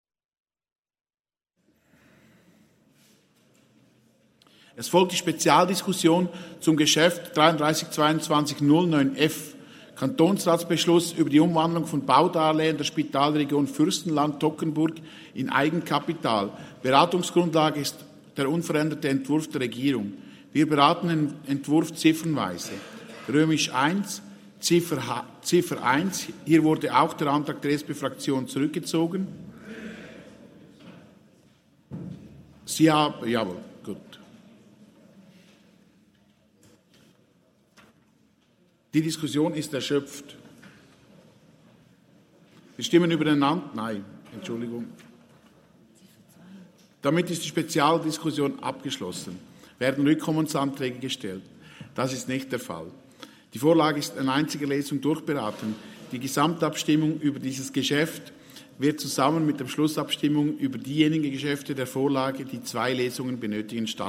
Session des Kantonsrates vom 28. bis 30. November 2022